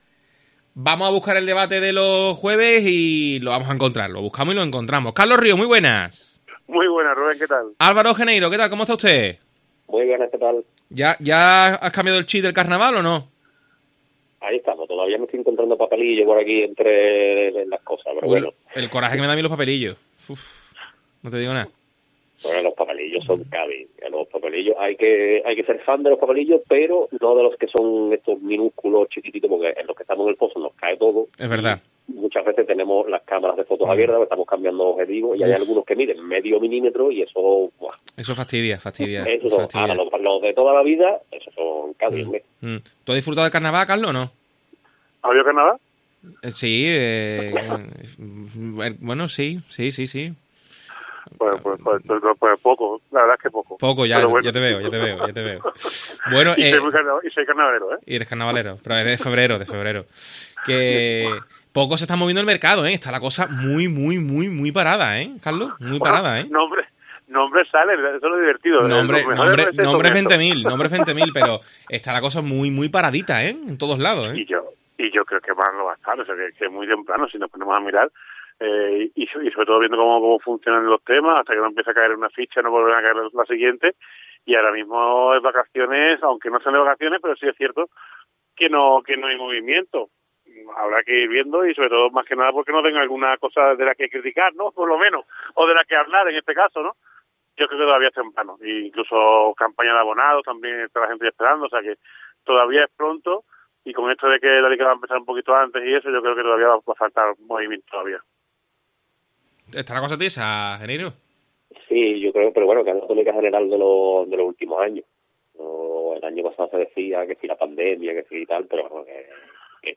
El debate de Deportes COPE (16-6-22)